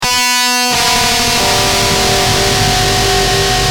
Screamb1.wav